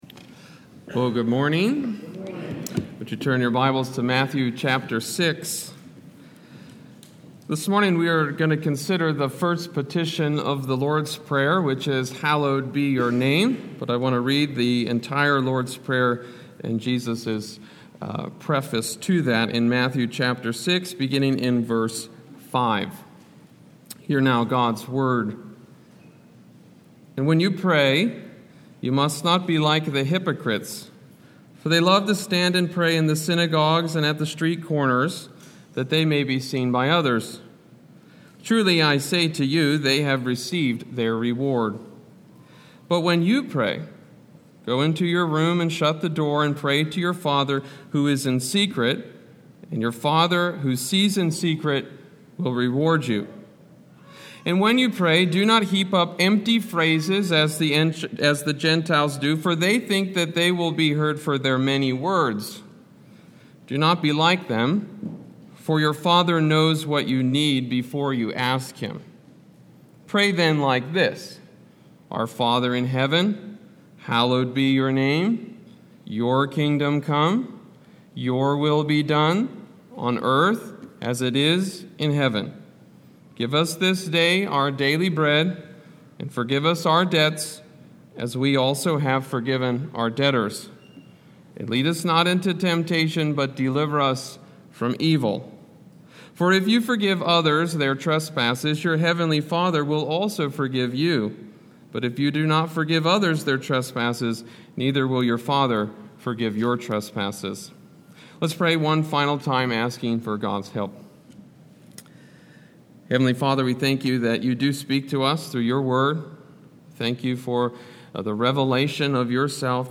Sermon-720.mp3